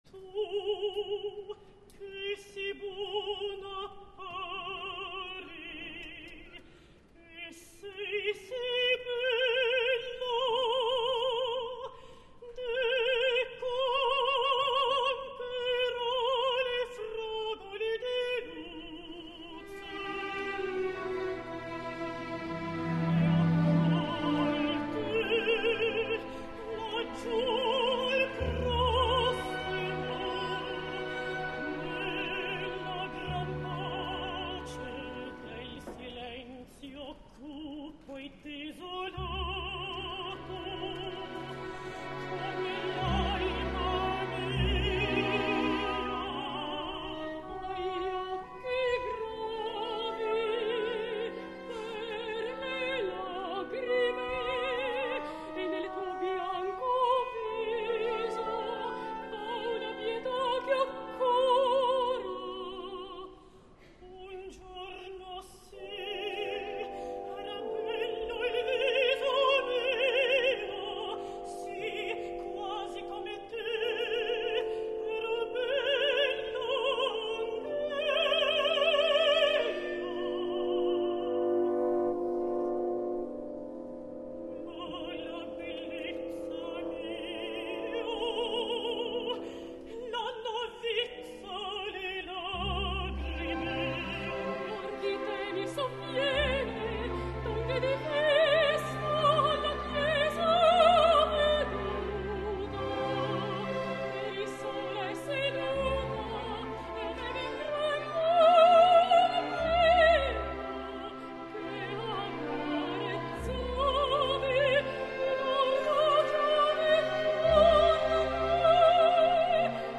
Verismo
Marussa [Sopran]
Luze [Alt oder Mezzo]